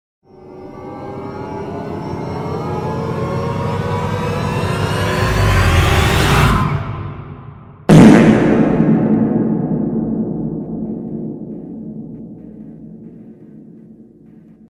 Scary Jumpscare Sound Button: Unblocked Meme Soundboard